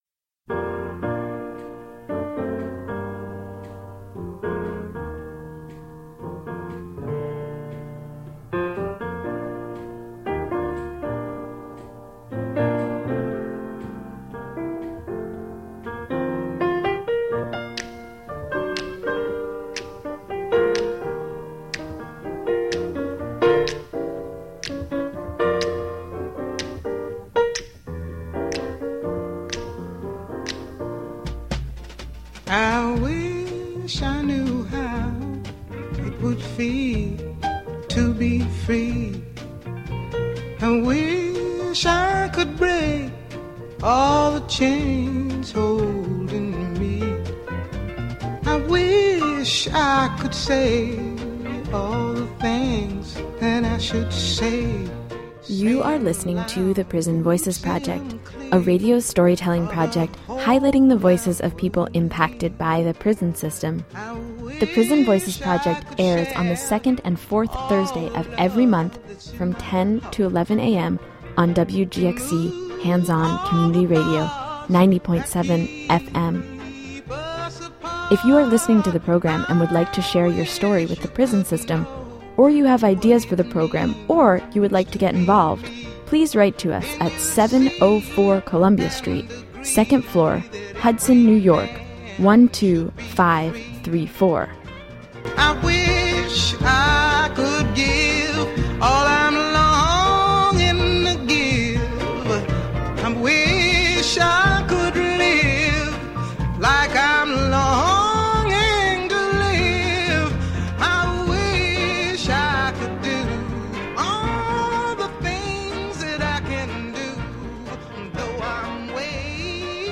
Prison education show.mp3